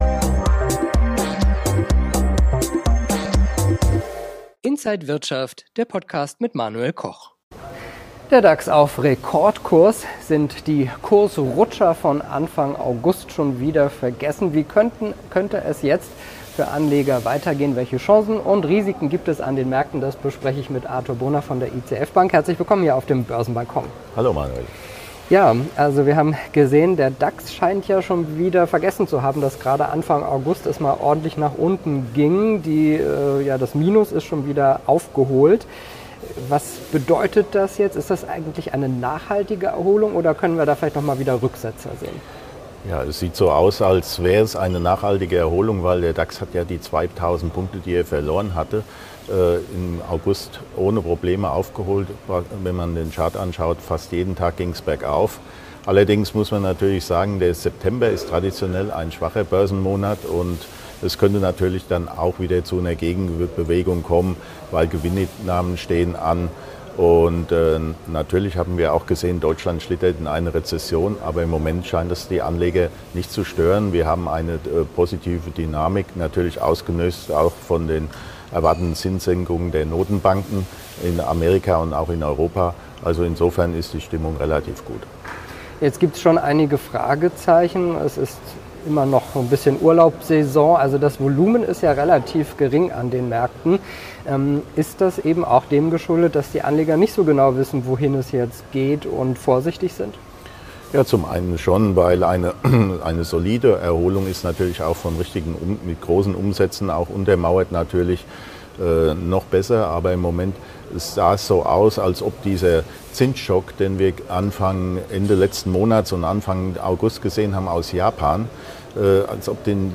Nvidia interessant? Alle Details im Interview von Inside